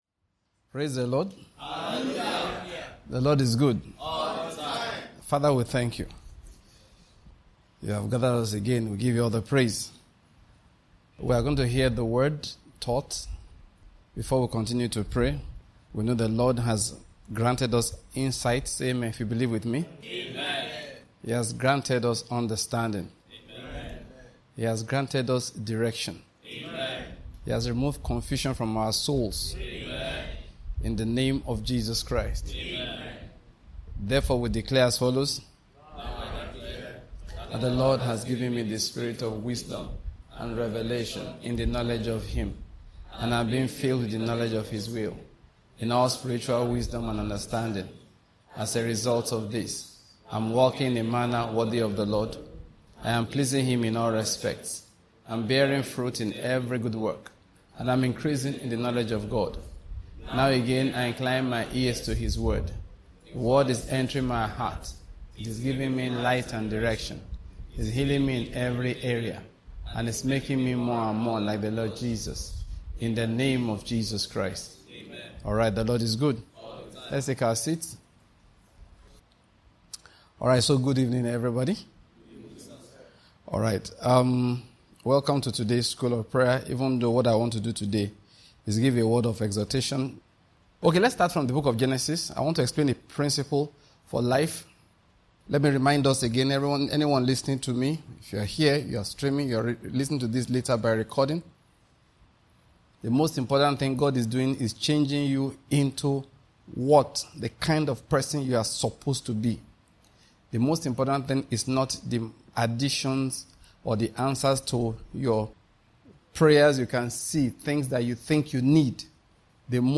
Keys of the kingdom. Exhortations